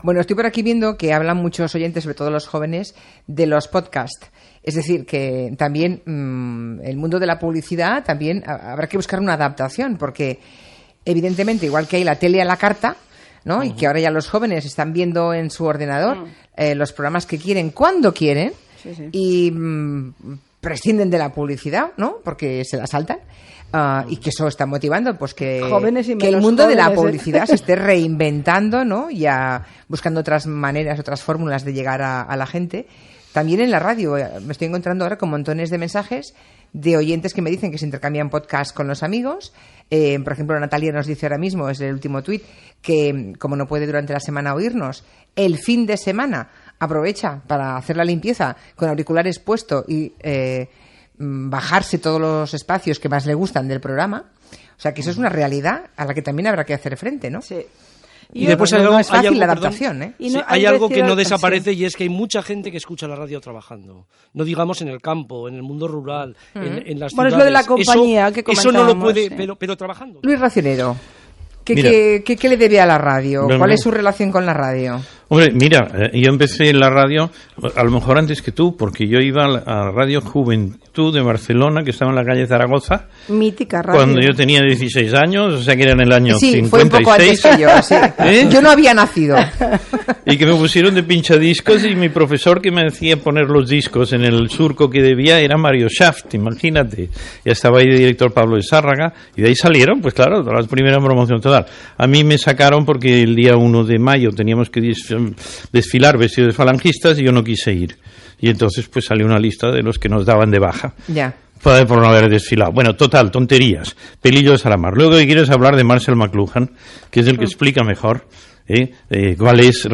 Secció "El Gabinete", del dia mundial de la ràdio, amb Julián Casanova, Luis Racionero i Pilar Rahola. Noves formes d'escoltar la ràdio, el fenomen dels pòdcasts, la funció i característiques de la ràdio, etc.
Info-entreteniment